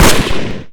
sol_reklam_link sag_reklam_link Warrock Oyun Dosyalar� Ana Sayfa > Sound > Weapons > Famas Dosya Ad� Boyutu Son D�zenleme ..
WR_fire.wav